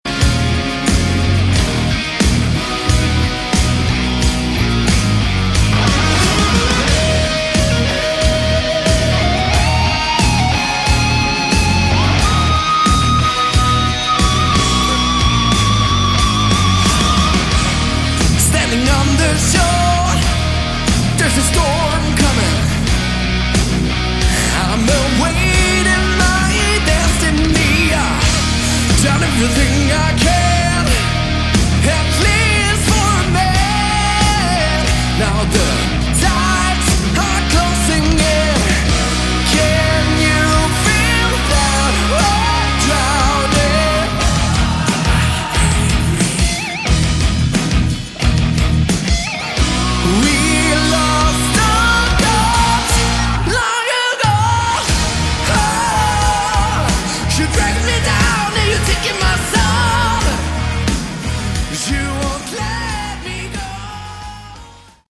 Category: Hard Rock
guitars
drums
vocals
bass
keyboards